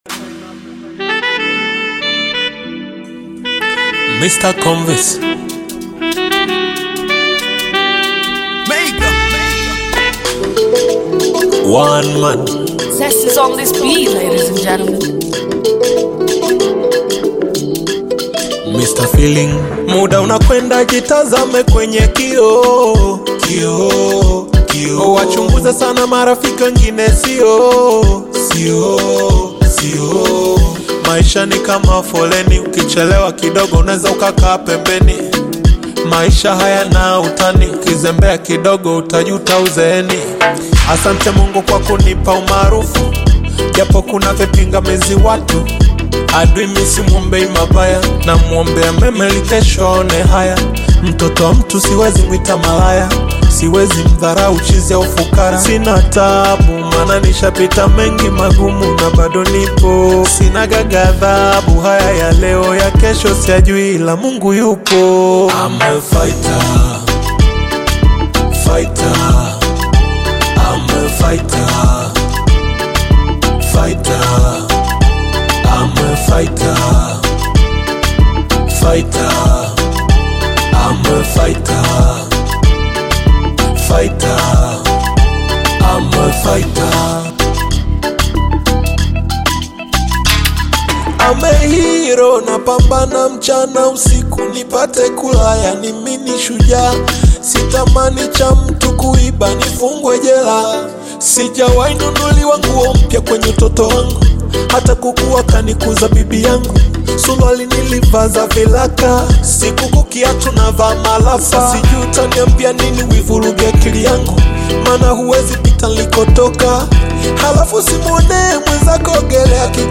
Tanzanian bongo flava artist, singer, and songwriter